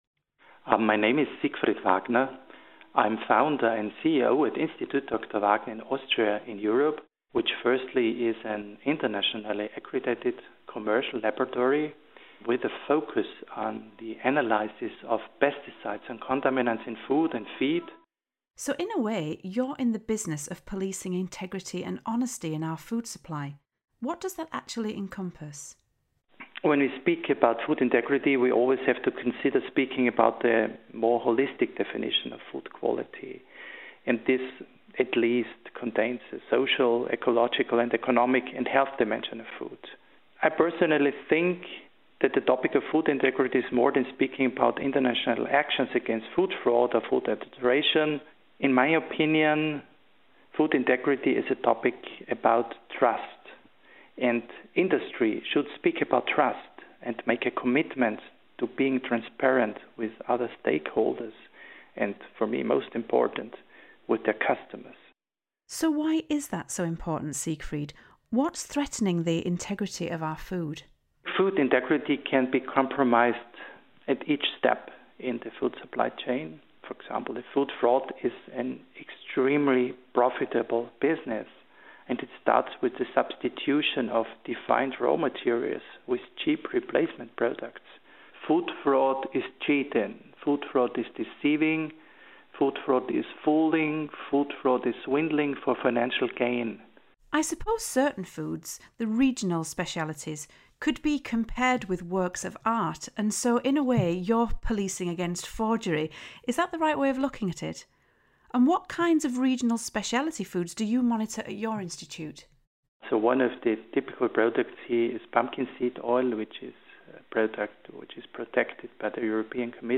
A podcast interview